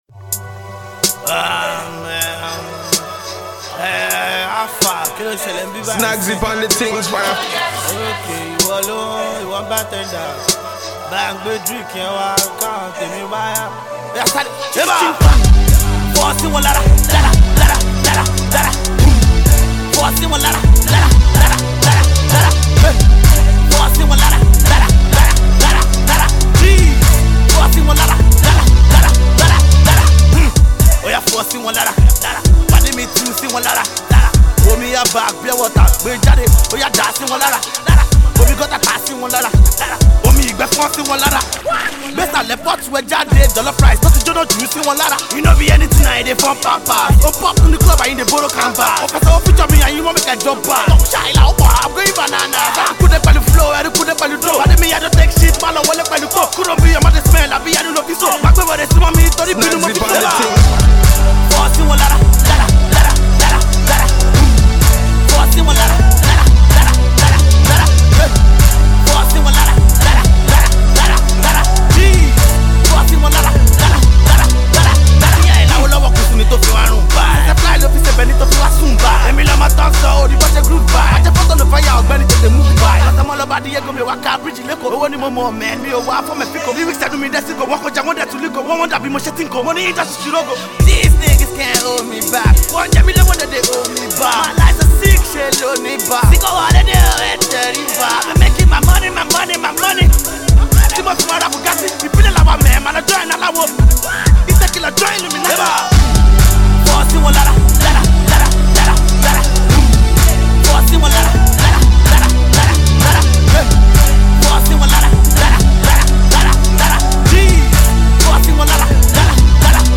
Alternative Pop
Smooth Trappy Vibe with some Indigenous yoruba Sauce